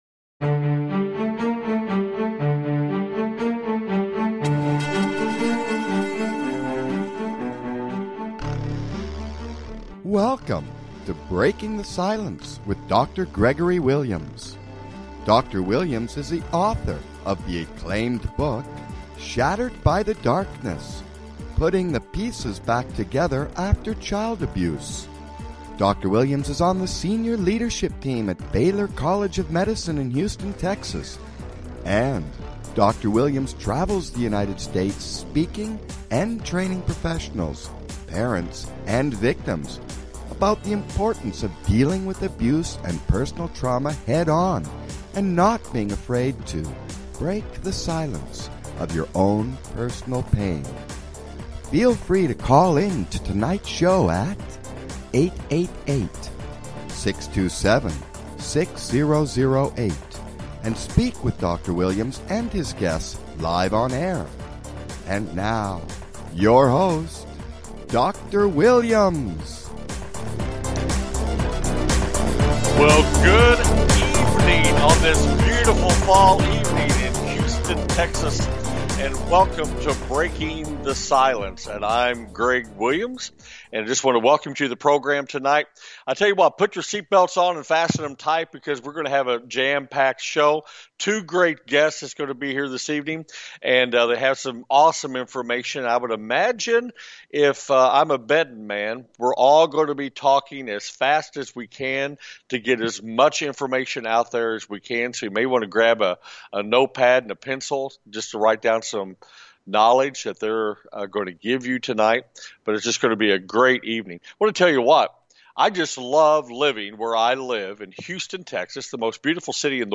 Breaking the Silence Talk Show